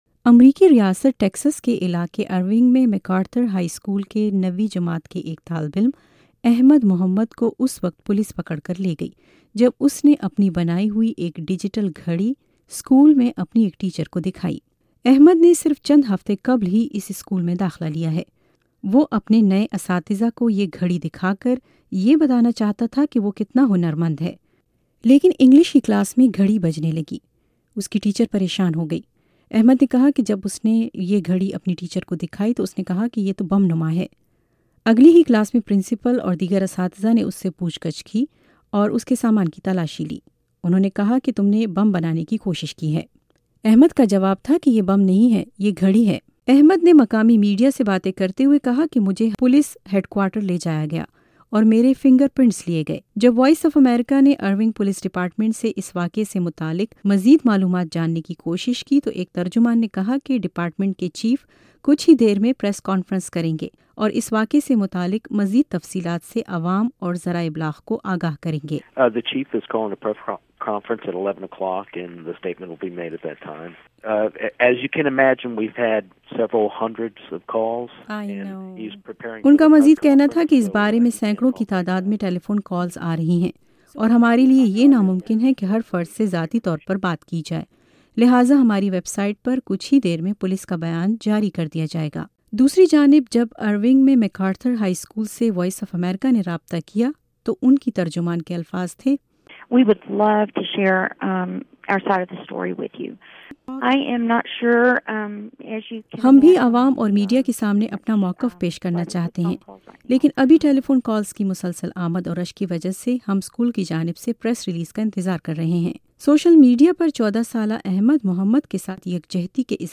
Redio report on Muslim student